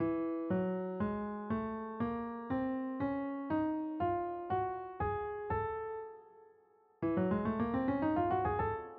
Ascending extensions part 2